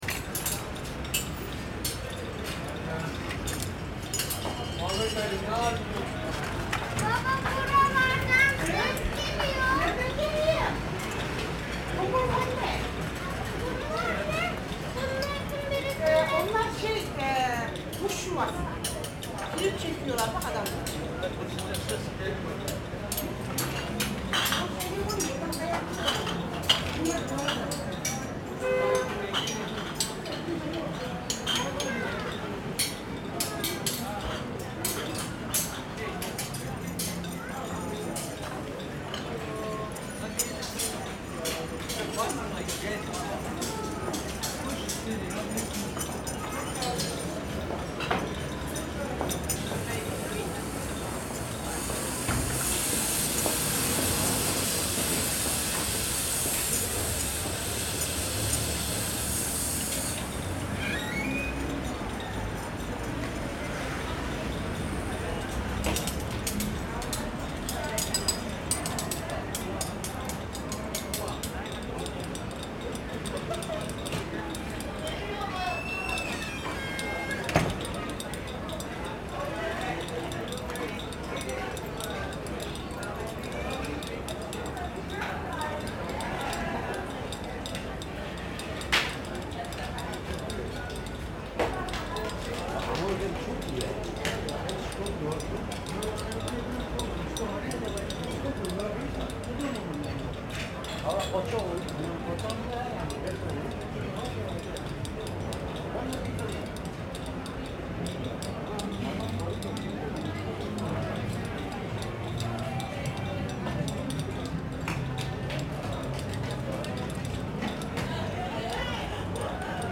Klangtour, Kottbuser Tor Berlin 2017
4 sound channels: speakers, bird cages, small lights. 20 min composed loop.
Polyphonic birds weaves abstract sounds into the dense urban public space.
This work is inspired by a squeaking fan found on a travel in a restaurant in Thessaloniki sounding like birds.
In the complex and busy acoustic urban space, the sounds of this intervention fade into the thick atmospheric whole of the city scape.